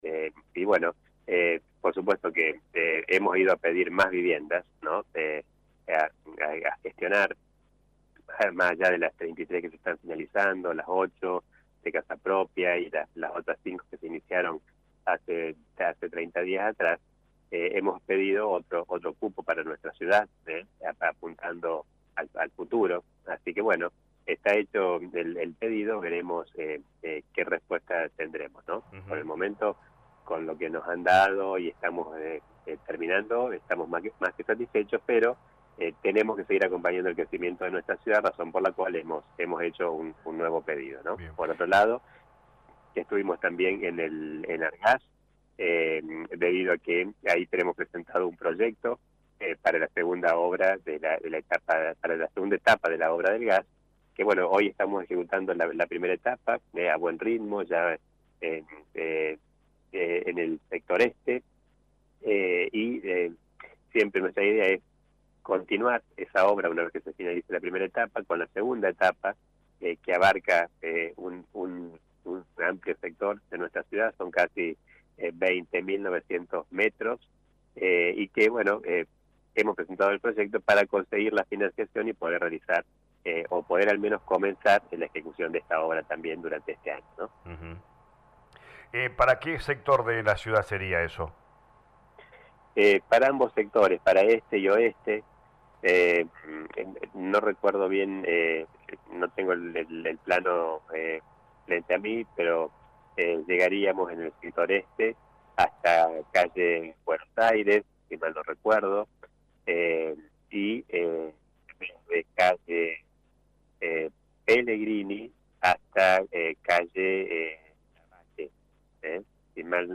El intendente Municipal Dr. Gustavo Tevez dialogó con LA RADIO 102.9 FM luego de su viaje a Ciudad Autónoma Buenos Aires donde realizó diferentes gestiones ante organismos nacionales.